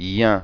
Afin d'illustrer le mécanisme de la nasalisation, je prononce d'abord la voyelle orale puis la nasalise en abaissant distinctement le voile du palais.
Je ne respecte pas — dans les illustrations sonores — la place de l'accent tonique mais offre grosso modo à chaque élément de diphtongue une durée similaire.